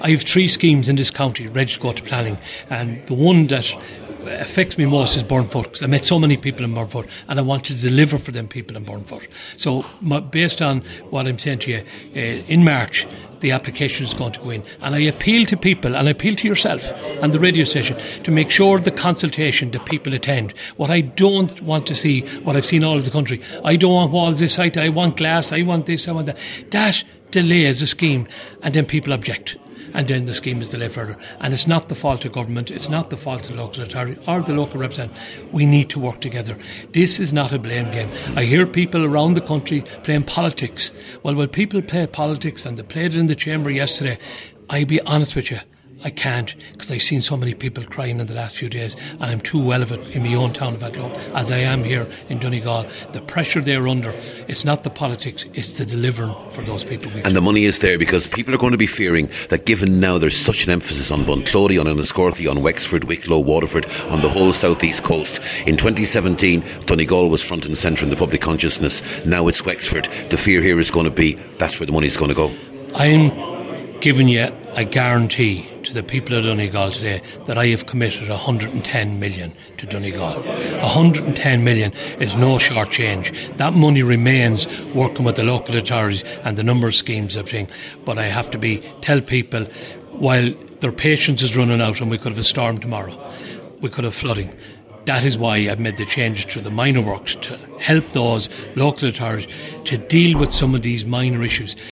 He told Highland Radio News that politics needs to be left at the door in order to get flooding relief schemes through the planning process……………..